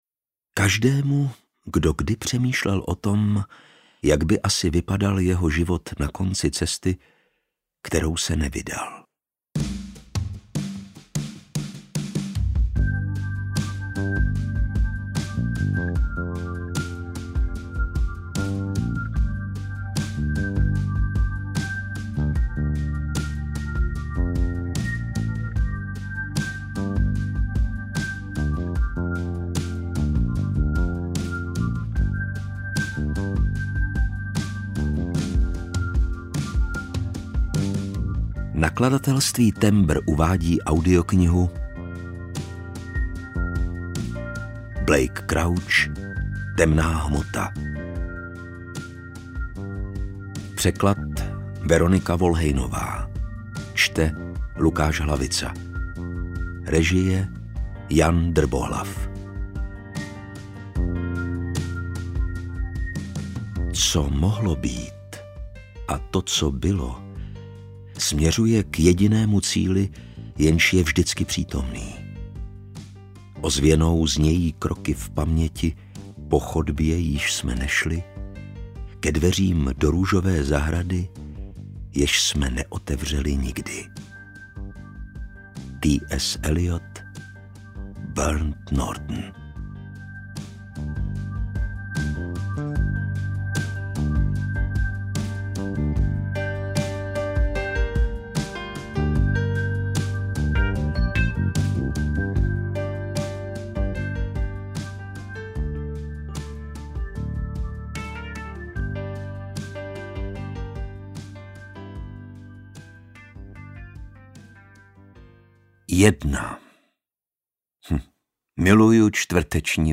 UKÁZKA Z KNIHY
Čte: Lukáš Hlavica
audiokniha_temna-hmota_ukazka.mp3